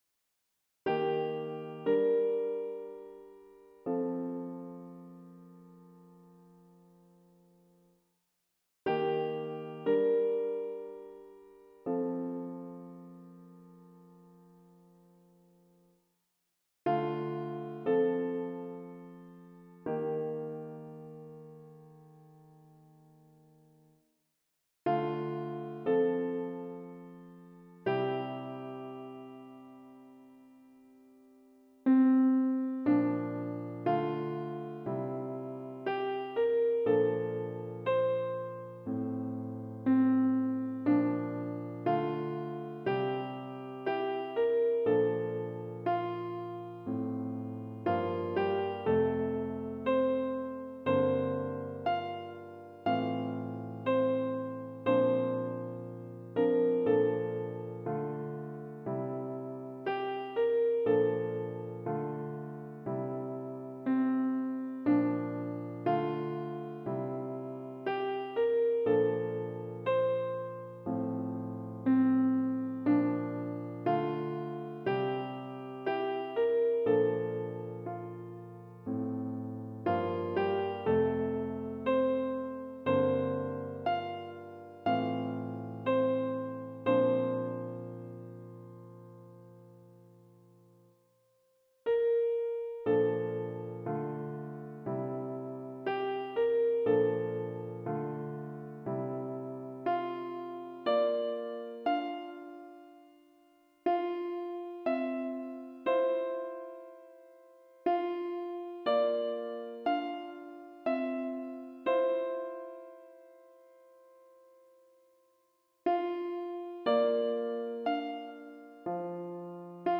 - Chant a capella à 4 voix mixtes SATB
Soprano Piano